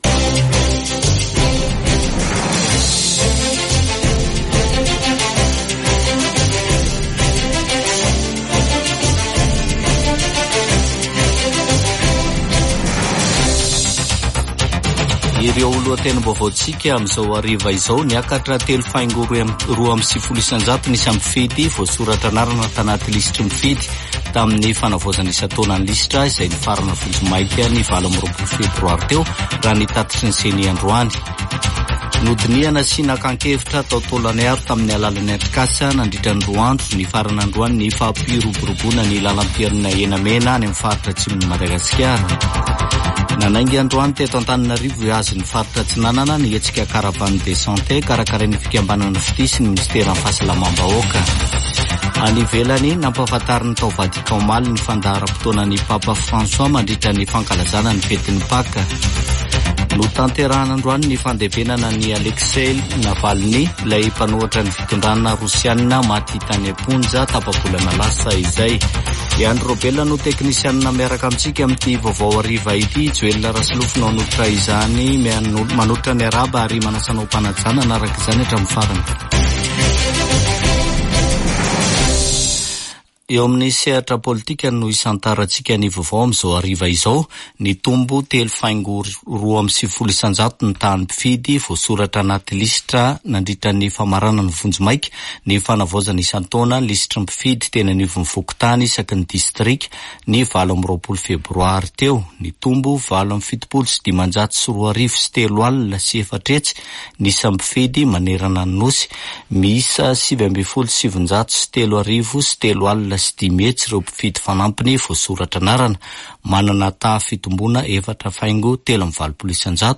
[Vaovao hariva] Zoma 1 marsa 2024